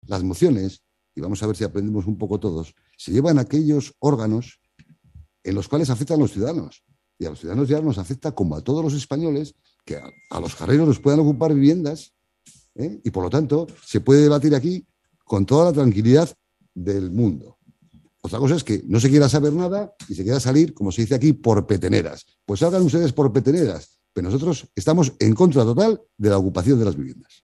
pleno
Unidas Podemos fue más tajante a la hora de posicionarse en contra de esta iniciativa popular “hay que regular sobre vivienda”. Así se explicaban Arantxa Carrero y Alberto Olarte.